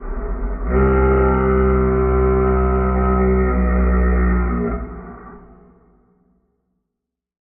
Minecraft Version Minecraft Version latest Latest Release | Latest Snapshot latest / assets / minecraft / sounds / item / goat_horn / call6.ogg Compare With Compare With Latest Release | Latest Snapshot